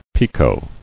[pe·koe]